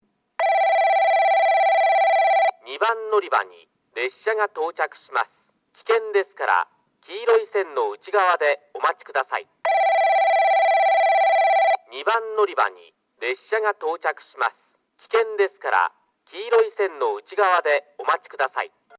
2番のりば　接近放送　男声
スピーカーはTOA防滴型、TOAラッパ型でした。